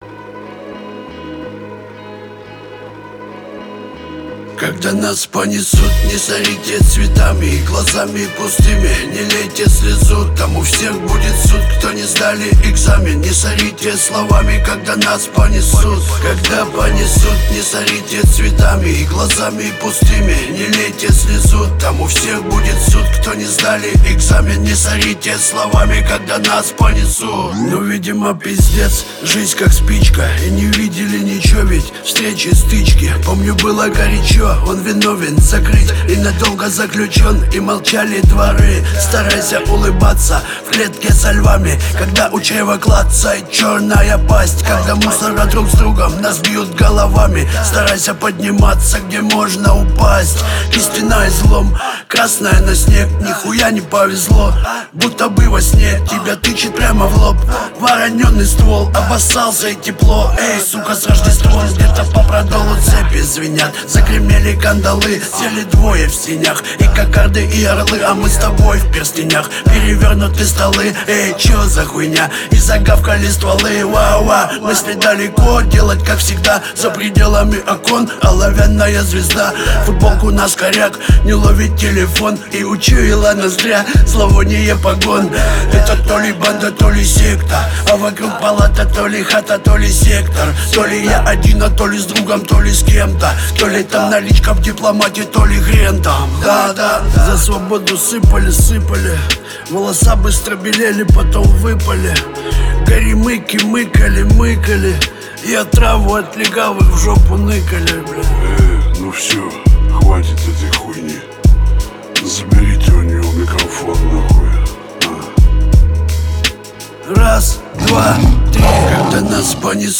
Реп